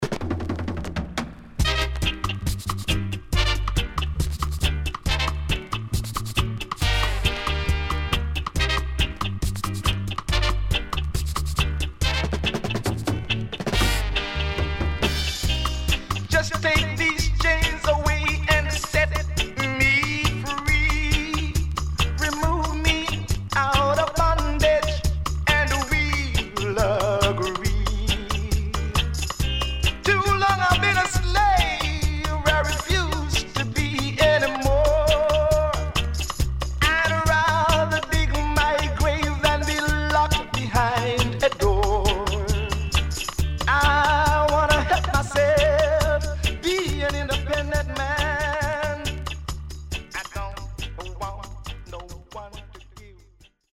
SIDE B:少しチリノイズ入りますが良好です。